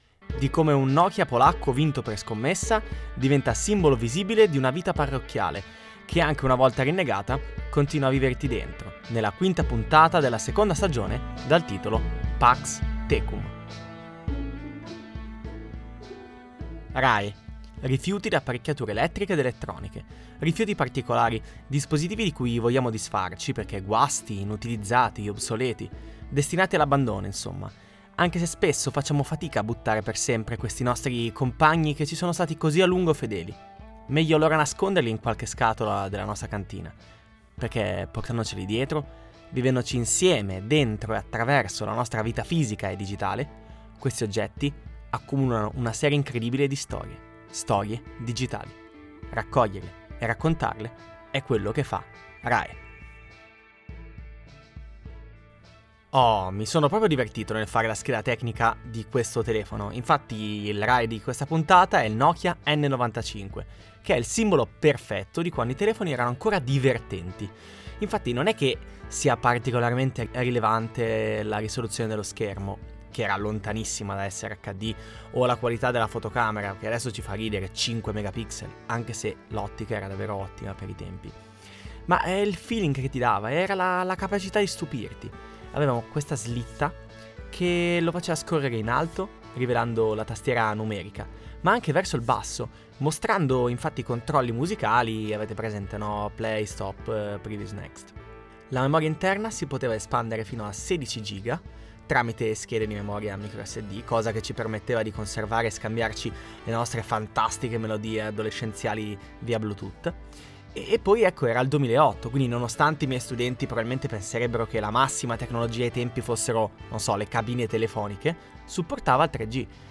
Audiodramma